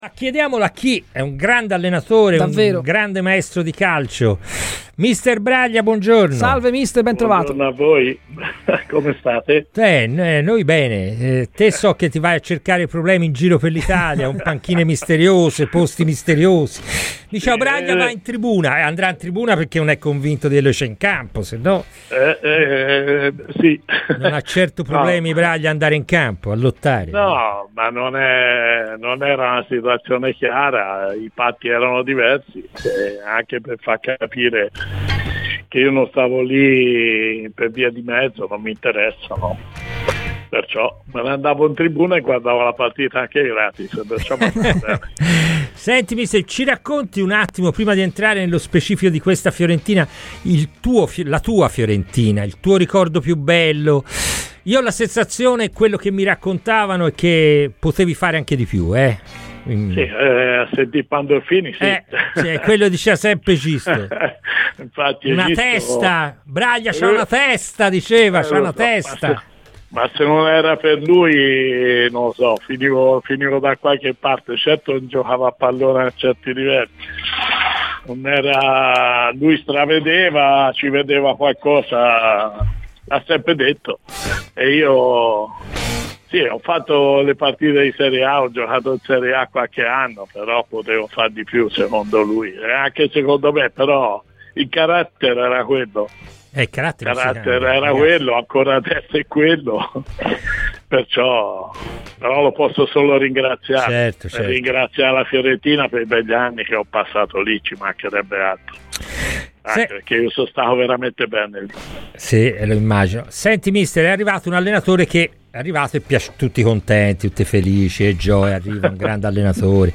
Clicca sul podcast per ascoltare l'intervista completa!